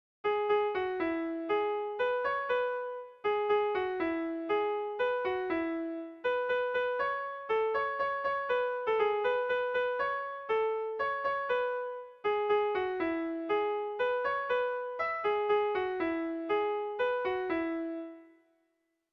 ABDAB